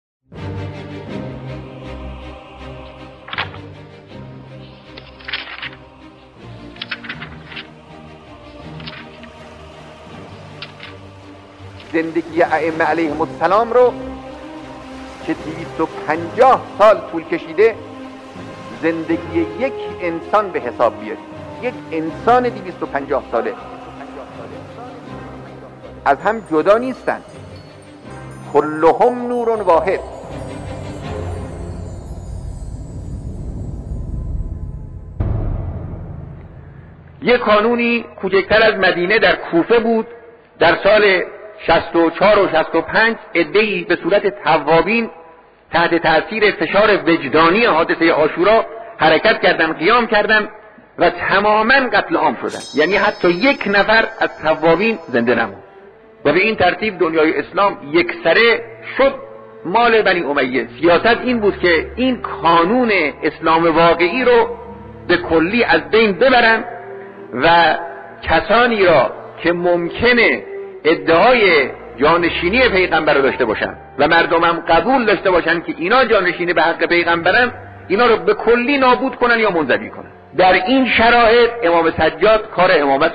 صوت سخنان رهبر معظم انقلاب اسلامی درباره شخصیت امام سجاد علیه‌السلام را در ادامه می‌شنوید.